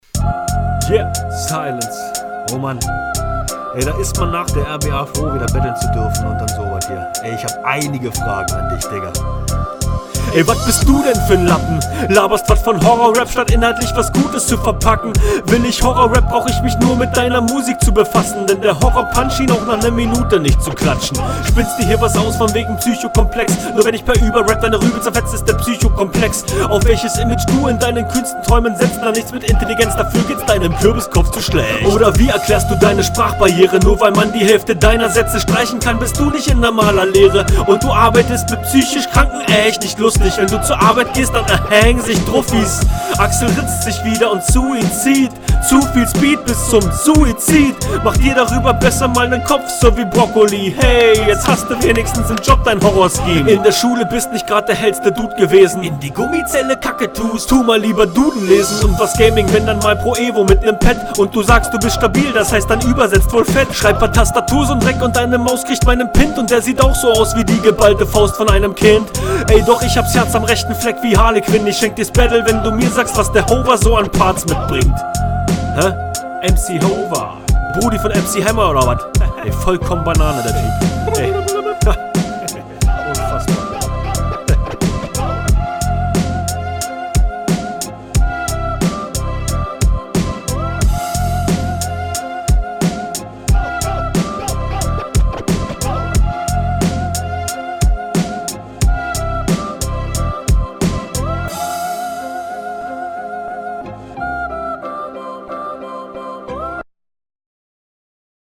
Flowlich kommst du besser als dein Gegner, du konterst sehr gut!